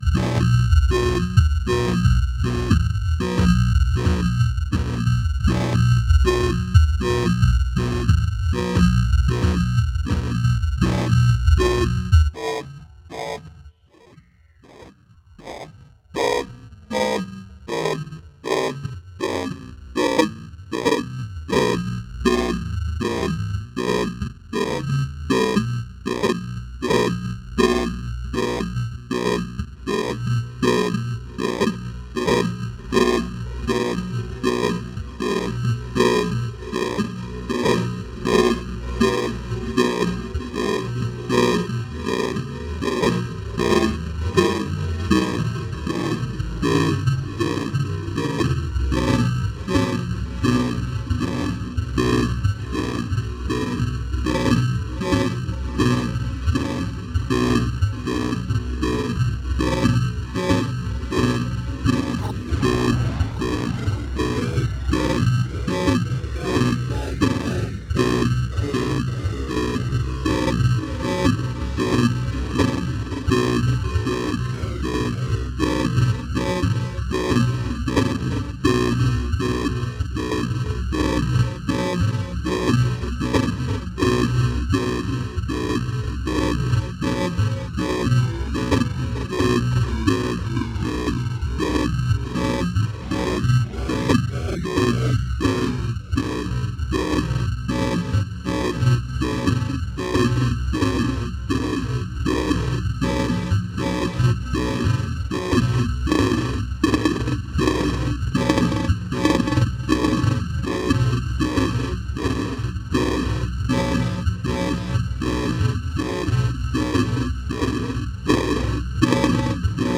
Downright sedate compared to recent posts but here’s some drone’n